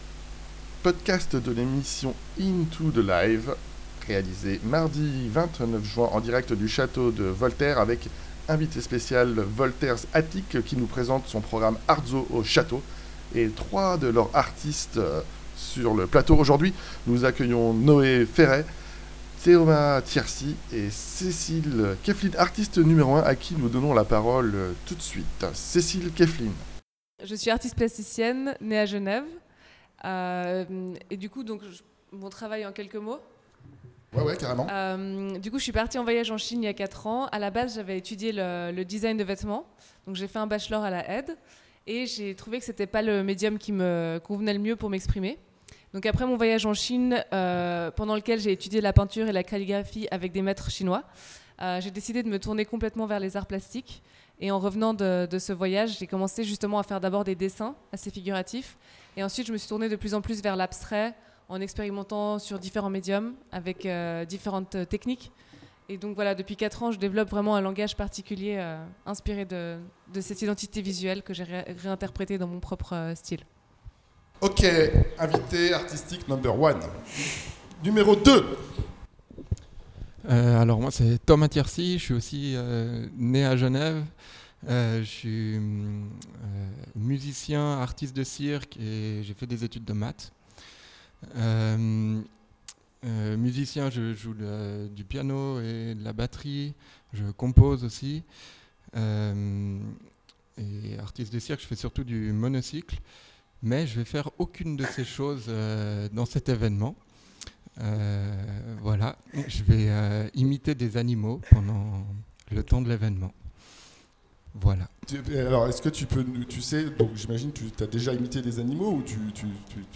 Interview decouverte de Artzoochateau